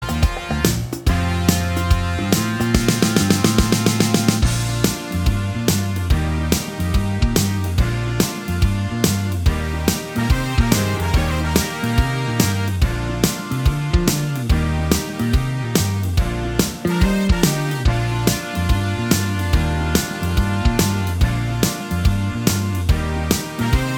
For Solo Male Pop (1990s) 3:46 Buy £1.50